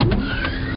elevator_str.wav